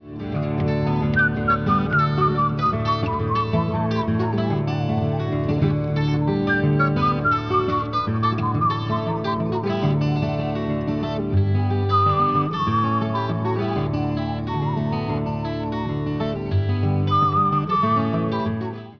12-string guitar, whistling